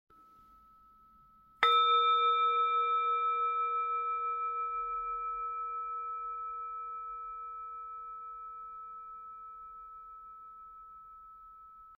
This sound healing frequency helps you sound effects free download